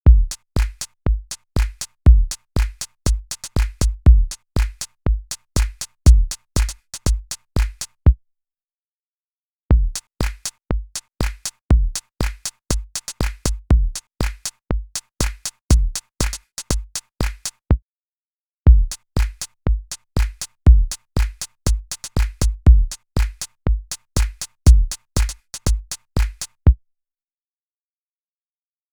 EQ45 | Drum Machine | Preset: Subby